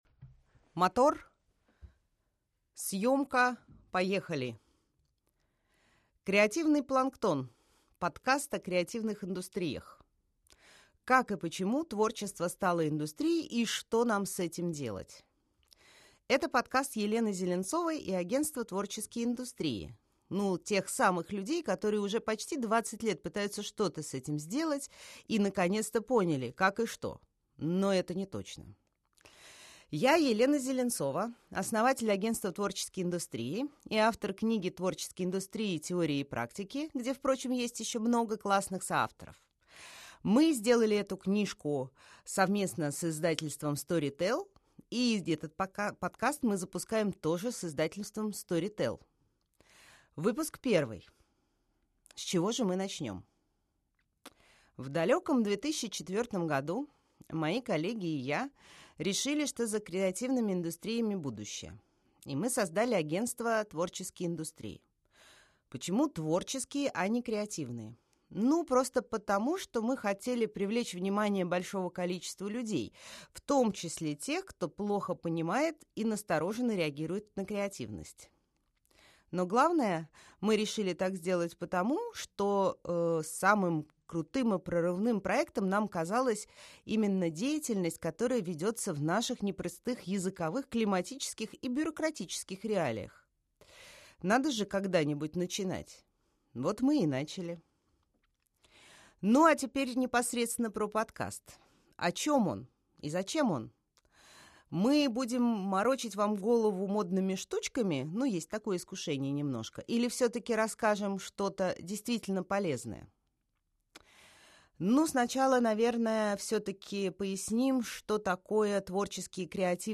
Аудиокнига Креативный планктон | Библиотека аудиокниг